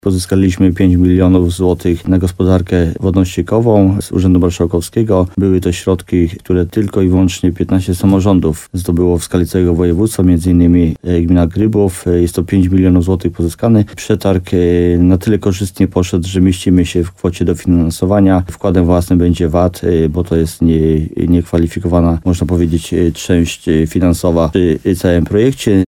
– Termin jest karkołomny, bo chcemy zakończyć inwestycję w listopadzie – mówił w programie Słowo za Słowo, wójt gminy Grybów Jacek Migacz.
Rozmowa z Jackiem Migaczem: Tagi: gmina Grybów Jacek Migacz sieć kanalizacyjna inwestycej wodno-kanalizacyjne robudowa Nowy Sącz Słowo za Słowo budowa